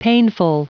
Prononciation du mot painful en anglais (fichier audio)
painful.wav